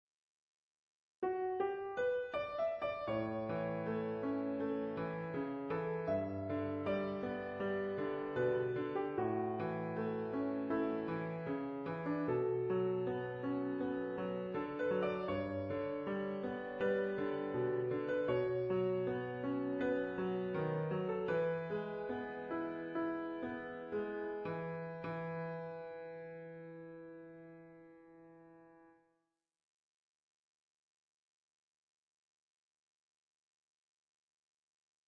Ballade für Klavier
Neue Musik
Pop/Rock/Elektronik
Sololiteratur
Klavier (1)